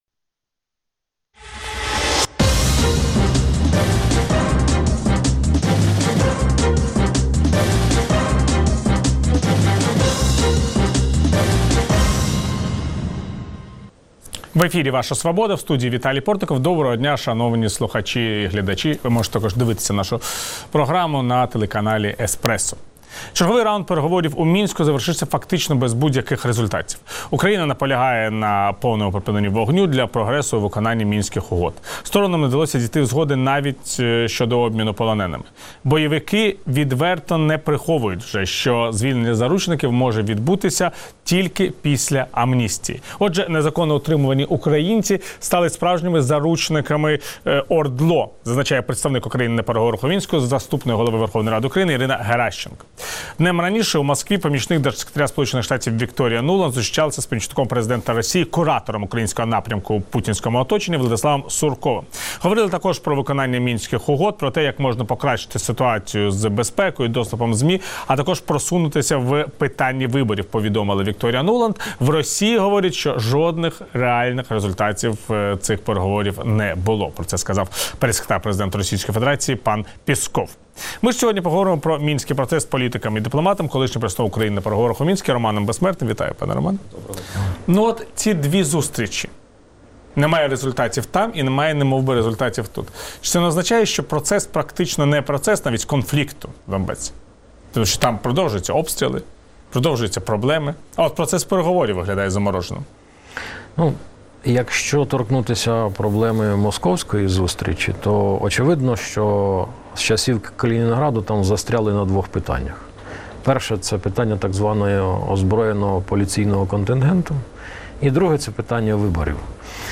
Гість: Роман Безсмертний, політичний діяч, дипломат